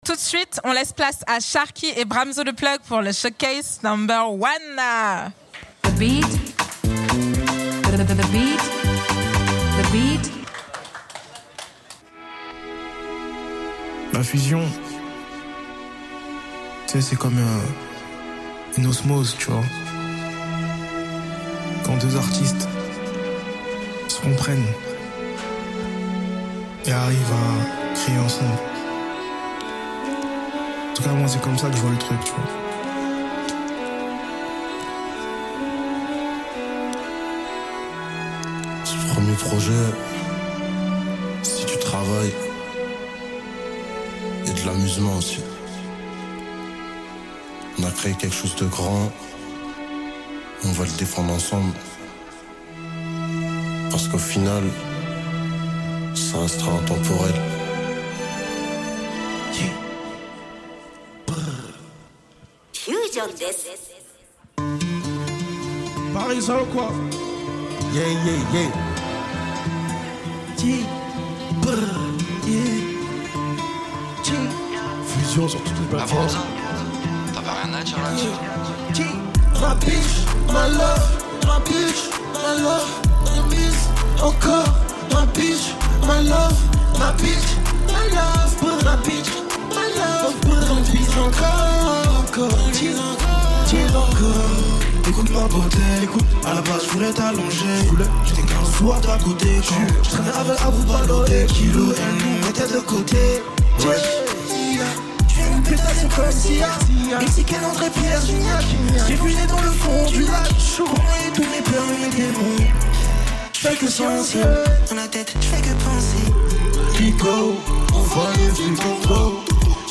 The Beat, The Culture fête l'été avec une édition spéciale de 5 heures en direct de La Place, centre culturel hip-hop !